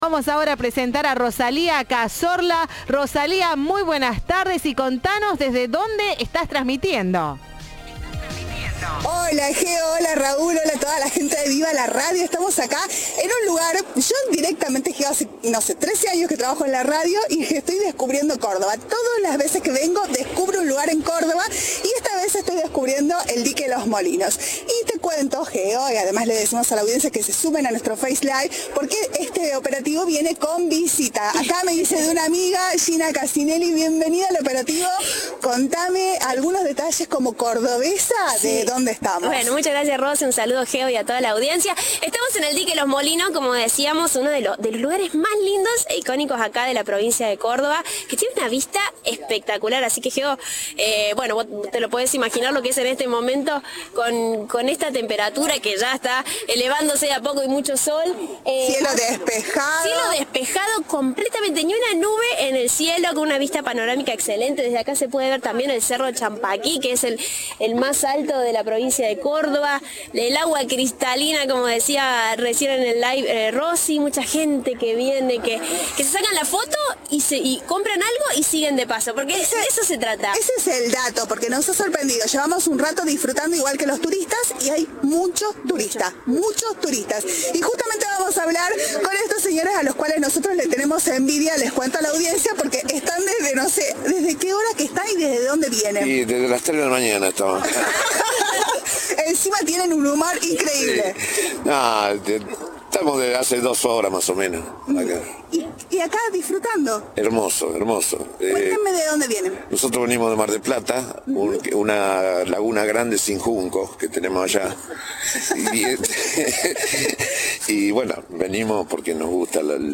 Cadena 3 estuvo en "Altos del Lago", uno de los miradores más lindos de la provincia. Tiene una vista panorámica desde donde se puede ver el cerro Champaquí.
Informe